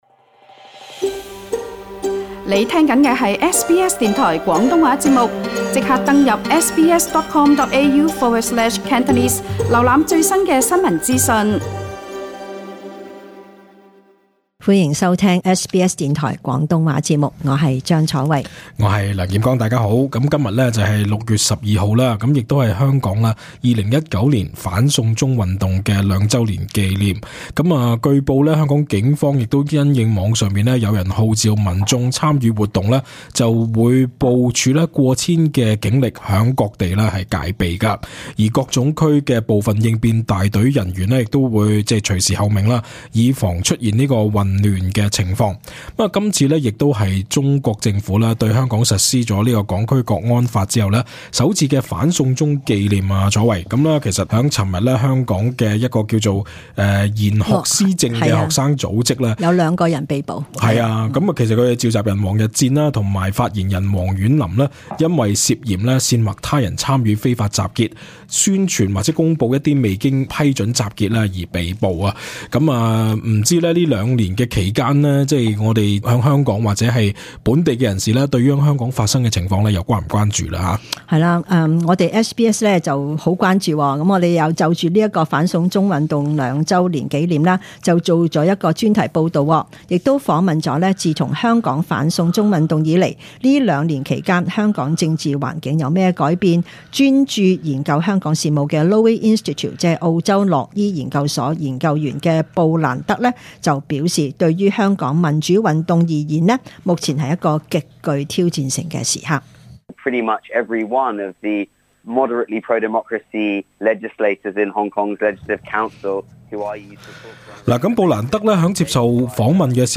星期六（6 月 12 日）是香港 2019 年「反送中運動」爆發兩週年紀念，同時亦是中國政府對香港實施「港區國安法」後首次「反送中」週年紀念。SBS 中文趁運動兩週年之際，訪問澳洲國會議員、流亡的前立法會議員、人權組織代表，以及本地港人團體，了解他們對運動和未來的看法。